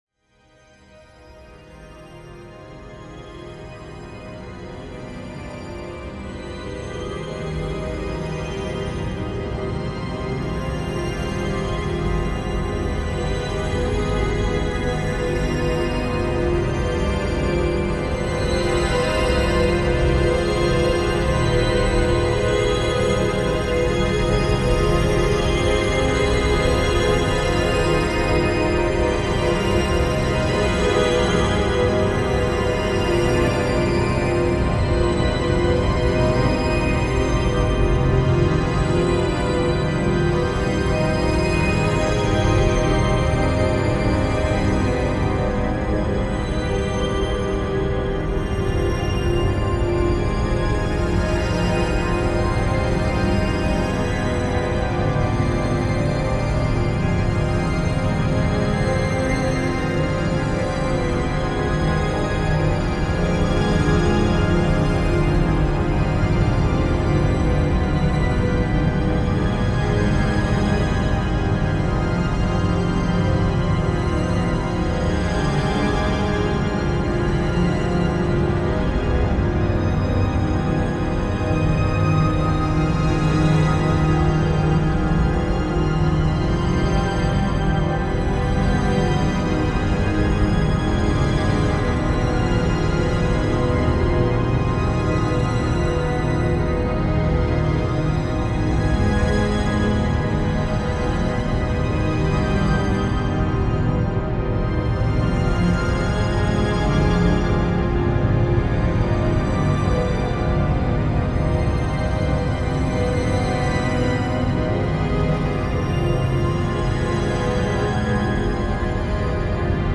本作がオルガンによる教会音楽的なアンビエントに聴こえるのは、そうした訳があったのかと合点がいきます。
ちなみに本作は、敢えて主調を明確にせず、長短の平行調のいずれへも移ろい、たゆたうような作曲が成されているそうです。
たいへんデリケートで複雑なパンニングと、スペクトル分布を特徴とするアルバムです。